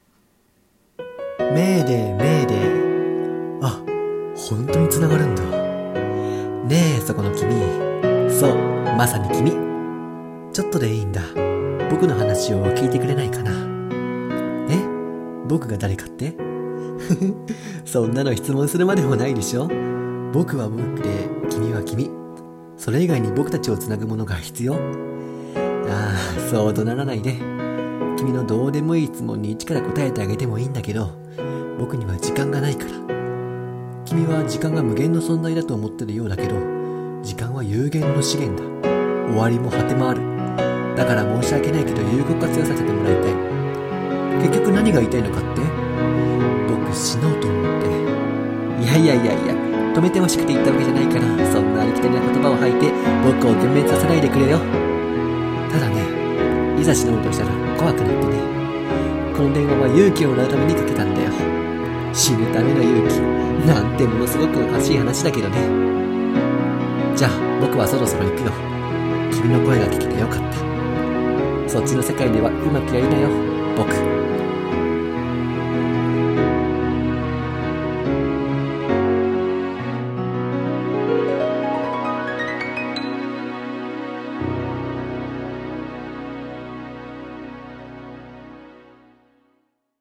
【声劇】さよならを知らない私へ